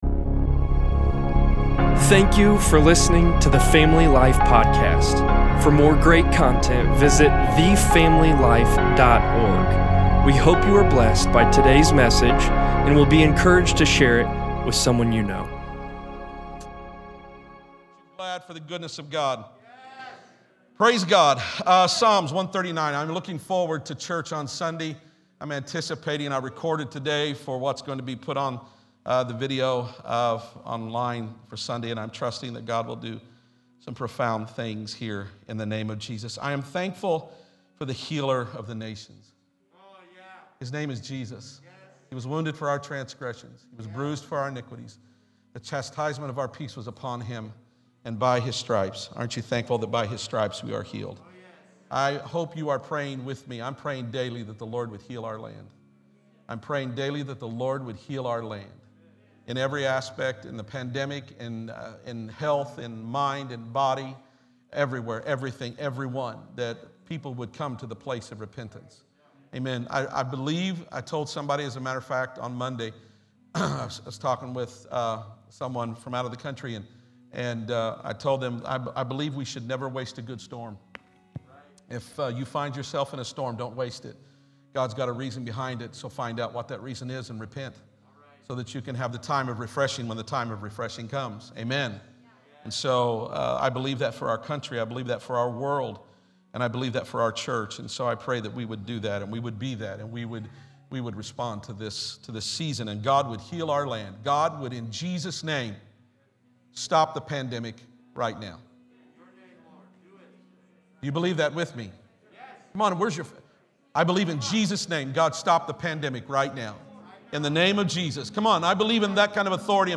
2.3.21_SERMON.mp3